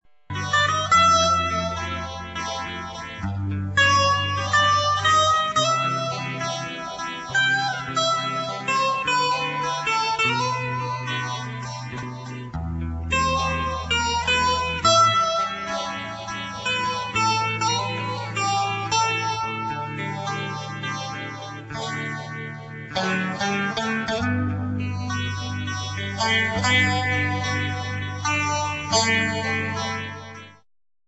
relaxed medium instr.